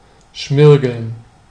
Ääntäminen
IPA: /ˈʃmɪʁɡl̩n/